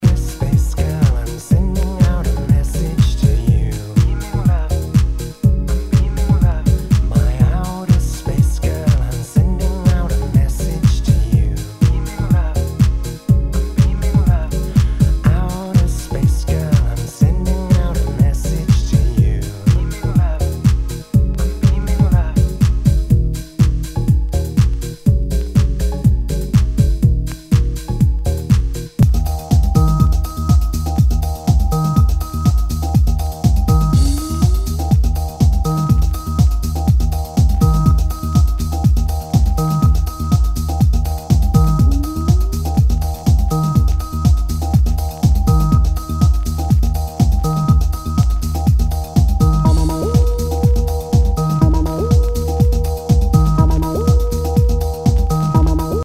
HOUSE/TECHNO/ELECTRO
全体にチリノイズが入ります